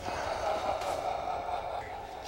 evil laughter.mp3
evil-laughter.mp3